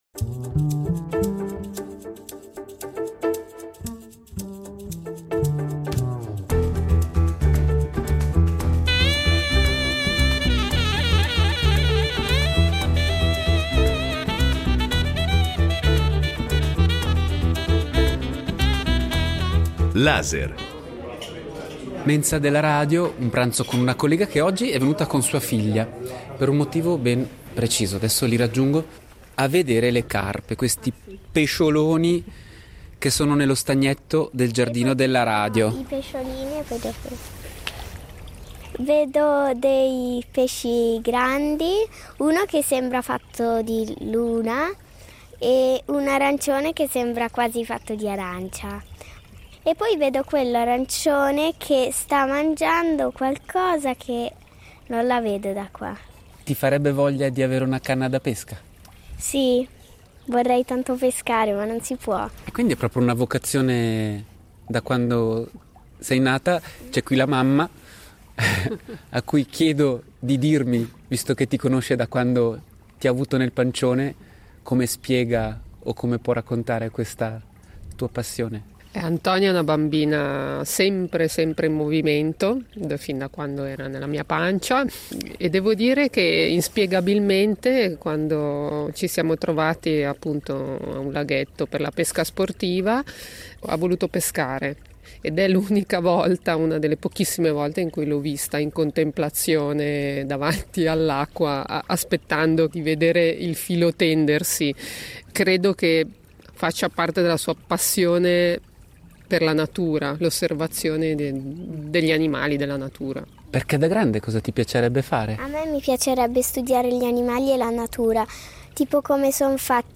Sono loro le protagoniste di questo documentario tutto peschereccio e… al femminile!